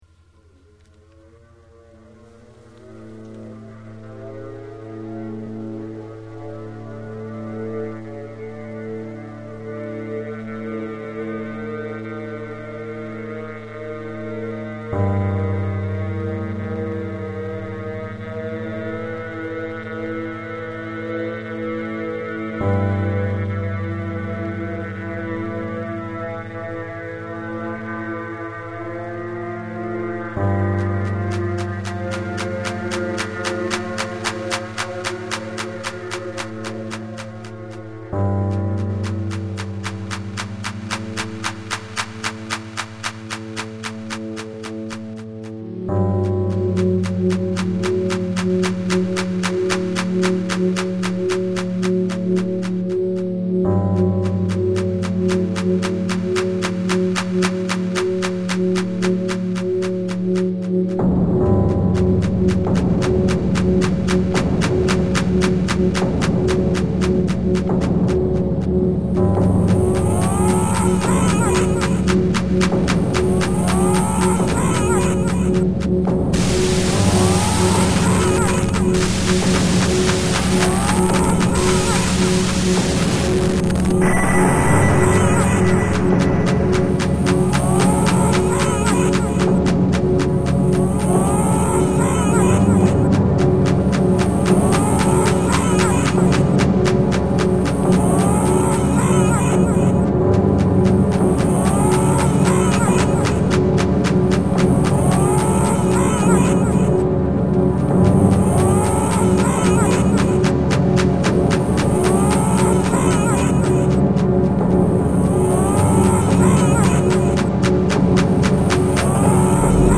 File under: Ambient / Industrial / Harsh Electronics
Dark / Isolationist atmopheres